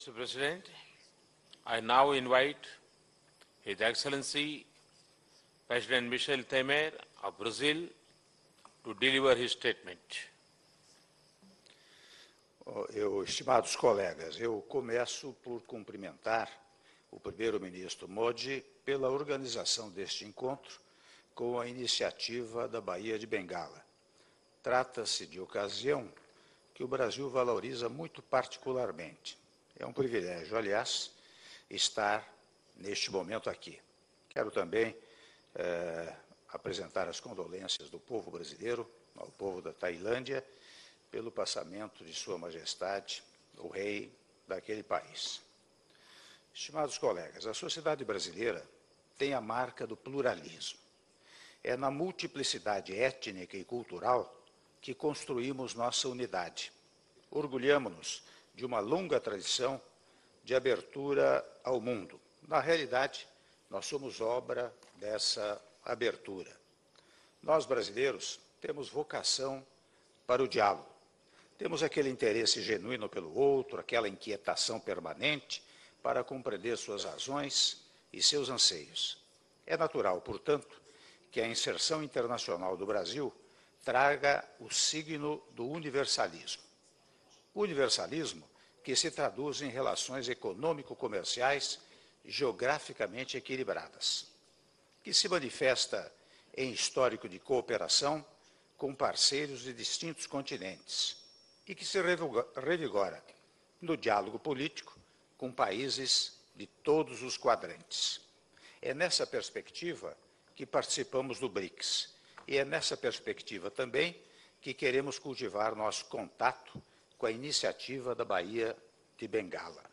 Áudio do discurso do presidente da República, Michel Temer, na Cúpula ampliada dos Chefes de Estado e de Governo do BRICS e do BIMSTEC - (07min54s) - Goa/Índia